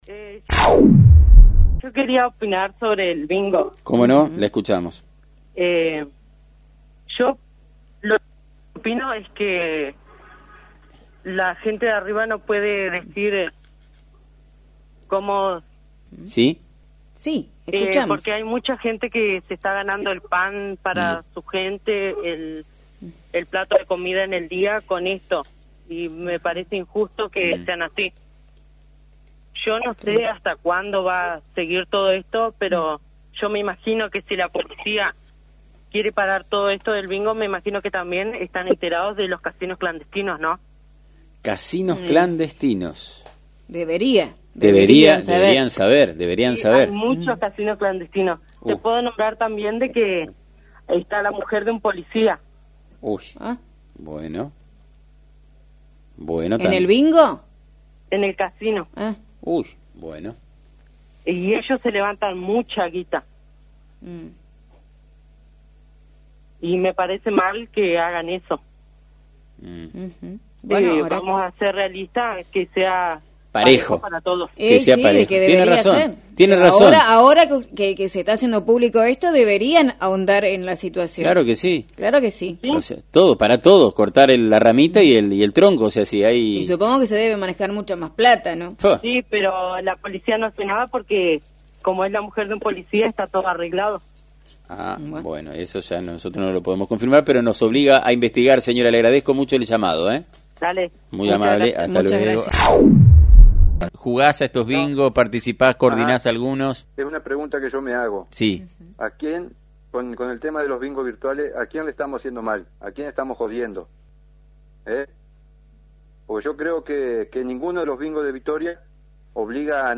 Reacciones de oyentes 1 en fm 90.3